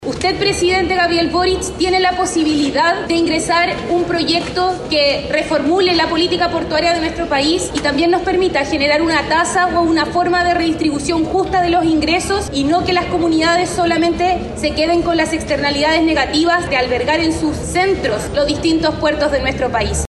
La alcaldesa Nieto, que estuvo presente en la sesión, lamentó el resultado y apuntó a las bancadas de la oposición por el resultado.